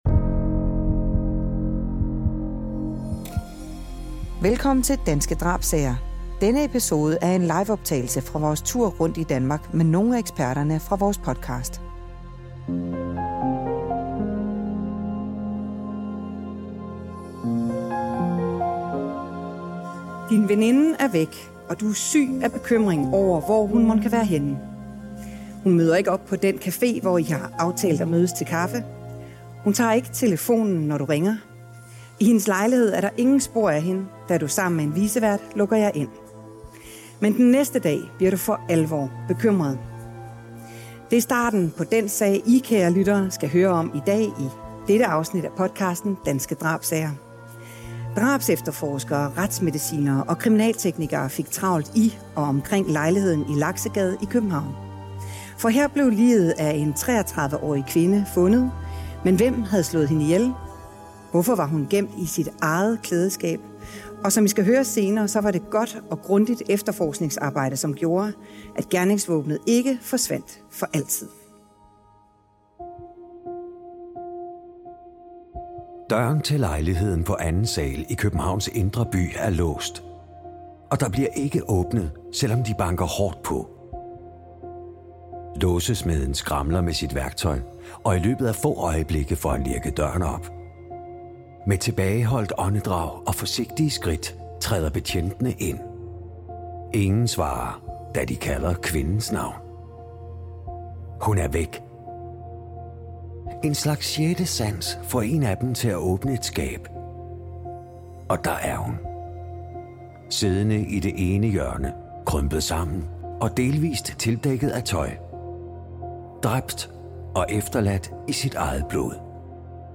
Det handler denne episode af Danske Drabssager om, og den er optaget live på scenen i Viften i Rødovre den 20. marts 2022.